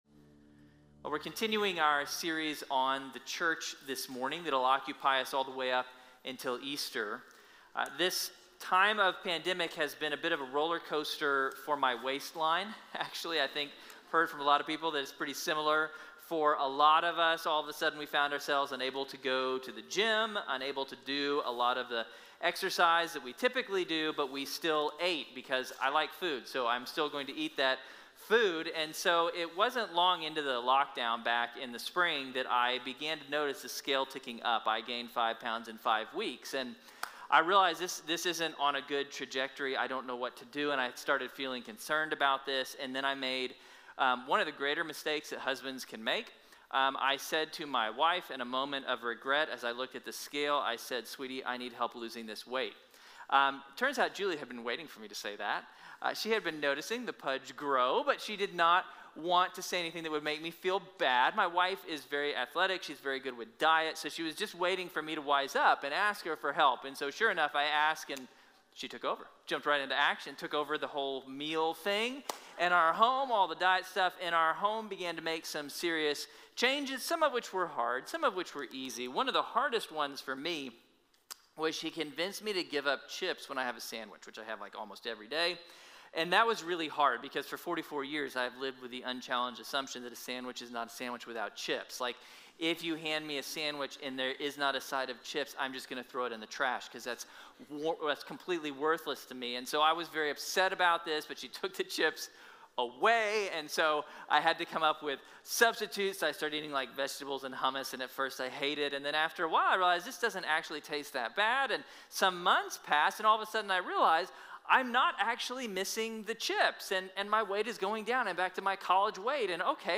| Sermon | Grace Bible Church